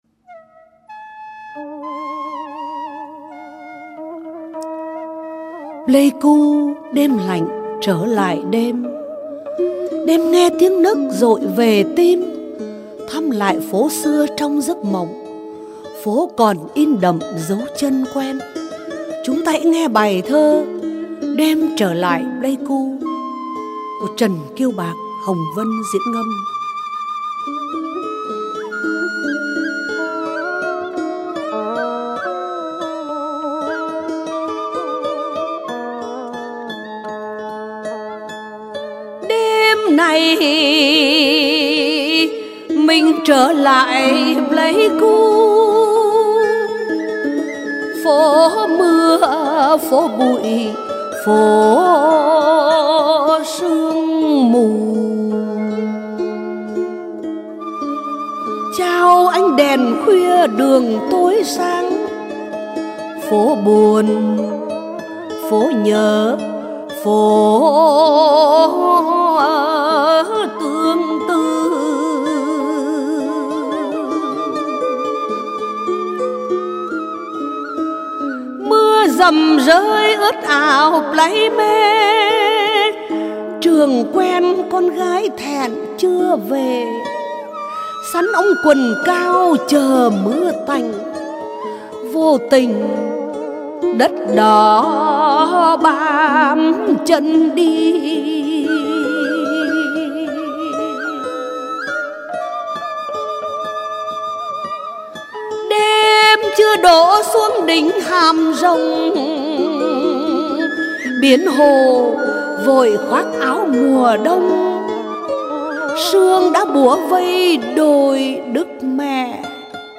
Ngâm Thơ | Sáng Tạo